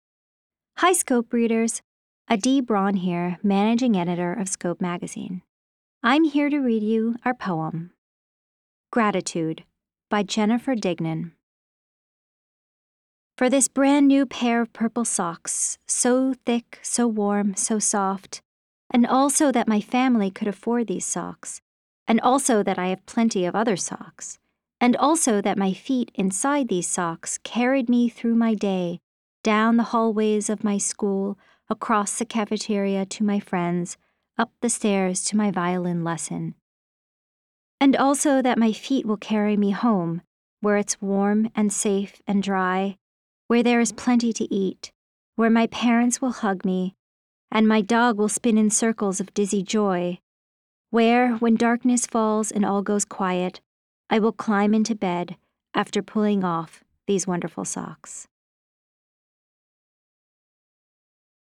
read aloud.